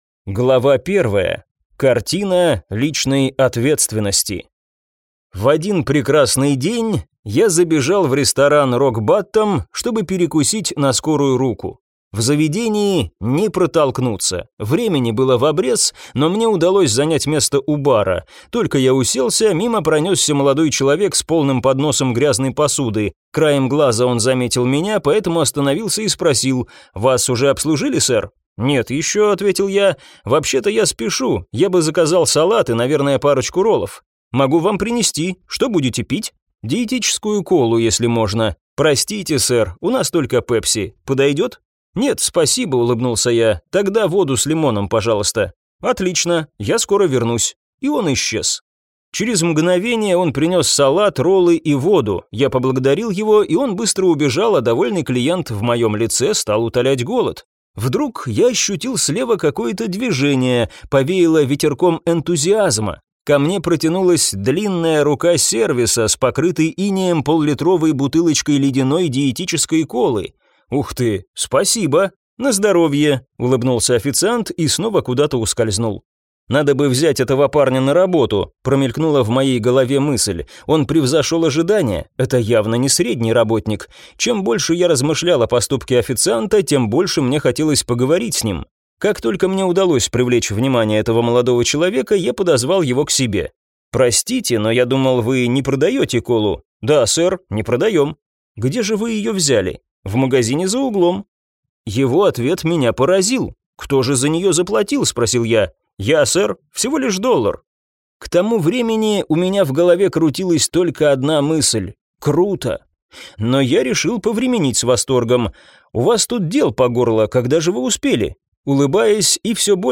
Аудиокнига Что я могу сделать? Как с помощью правильных вопросов перезапустить свою жизнь | Библиотека аудиокниг